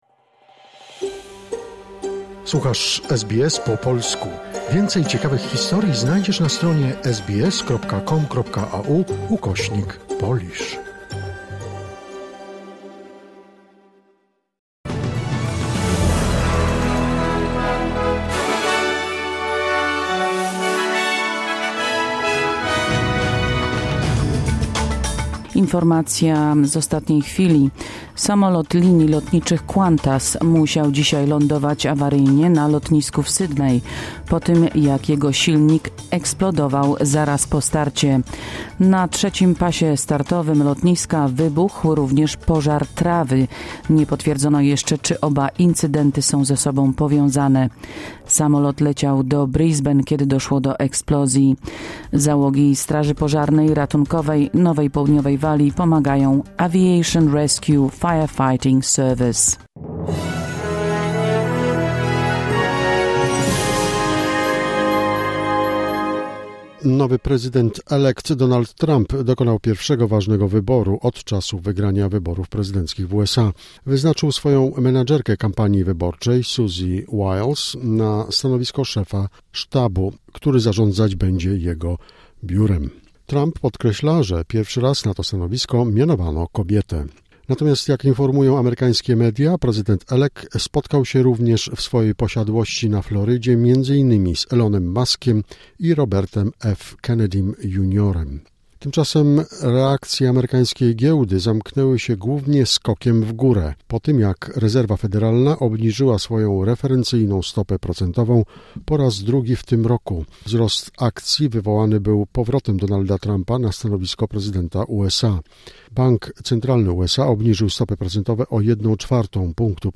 Wiadomości 8 listopada SBS News Flash